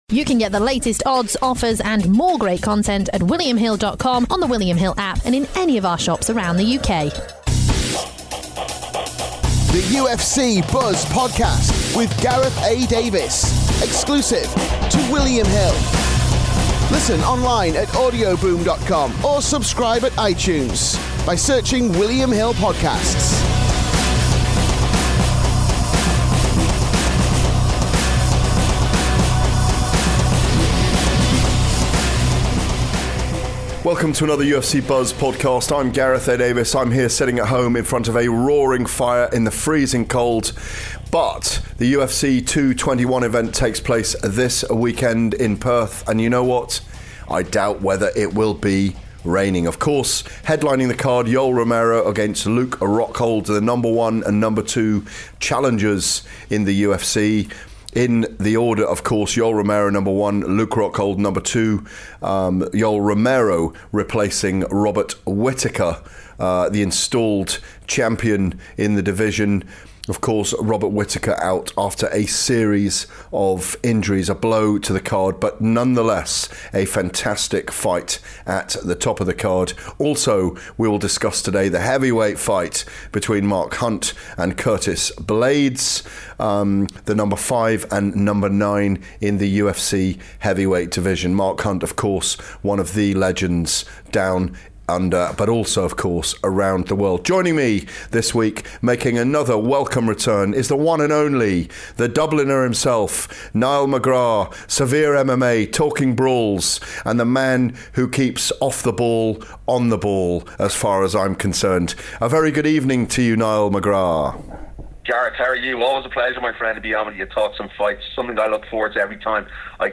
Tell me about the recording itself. at home in front of a blazing fire